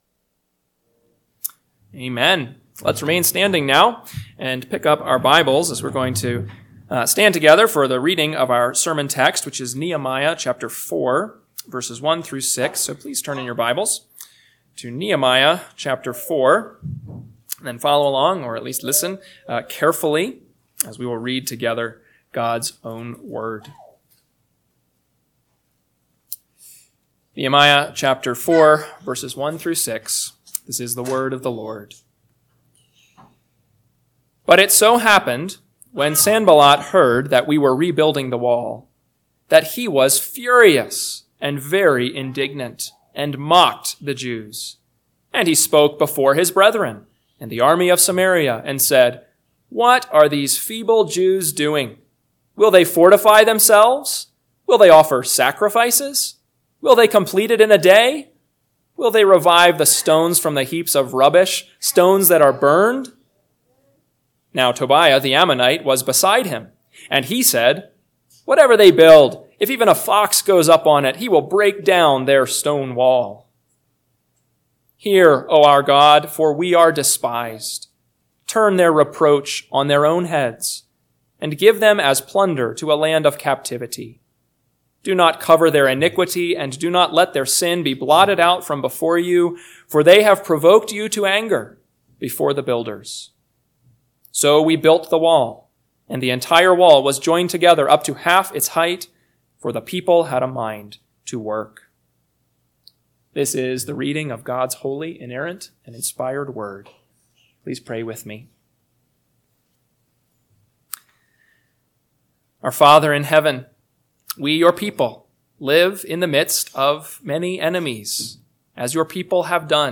PM Sermon – 7/20/2025 – Nehemiah 4:1-6 – Northwoods Sermons